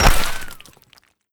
BulletImpact_Concrete04.wav